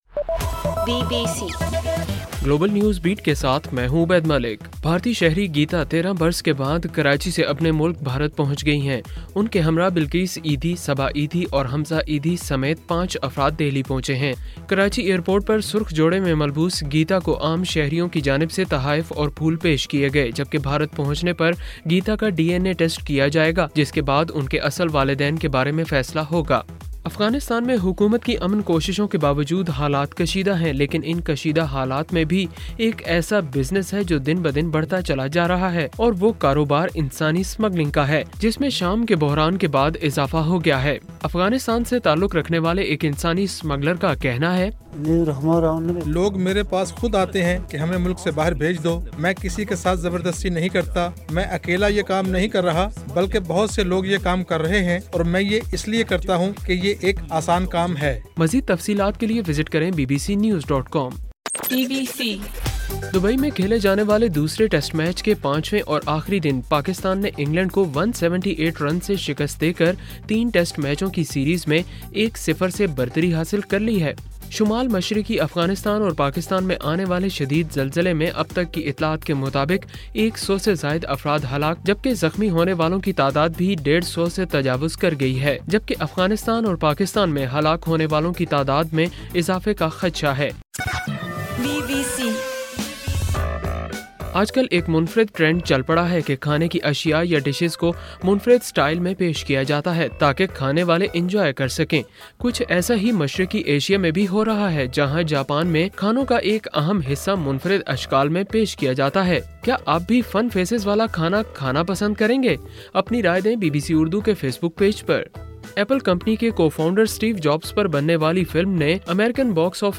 اکتوبر 26: رات 9 بجے کا گلوبل نیوز بیٹ بُلیٹن